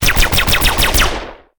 machine1.ogg